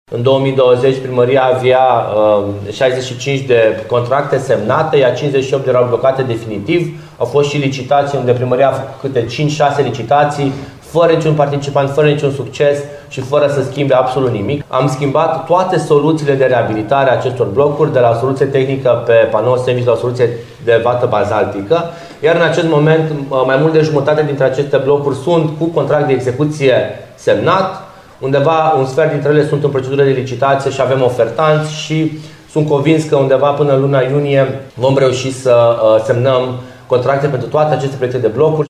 Viceprimarul Ruben Lațcău anunță că a fost necesară inclusiv schimbarea soluțiilor de reabilitare pentru ca firmele de construcții să poată participa la licitații.